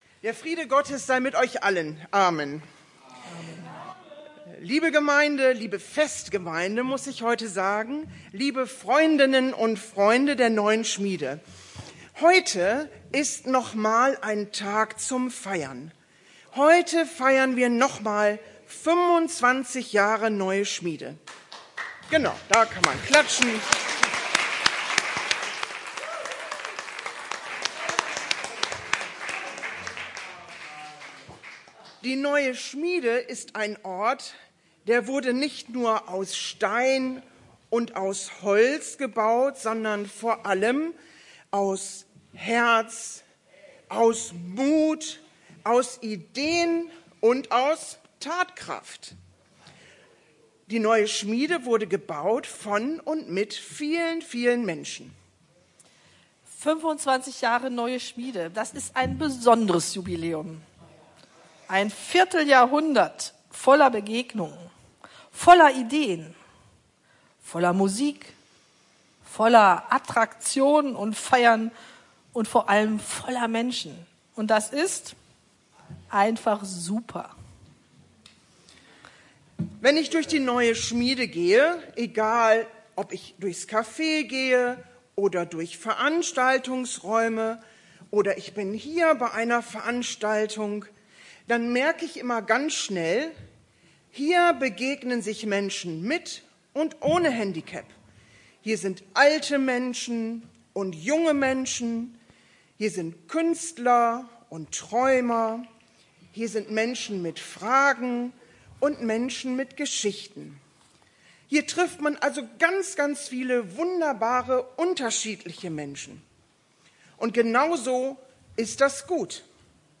Predigt des Gottesdienstes in einfacher Sprache zum 25-jährigen Jubiläum der Neuen Schmiede
Am Sonntag, den 21.09.2025 endete die Jubiläumswoche der Neuen Schmiede mit einem Gottesdienst in einfacher Sprache. Die Predigt des Gottesdienstes stellen wir Ihnen hier zum Nachhören bereit.